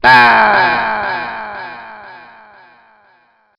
voice_aw_aw_aw.wav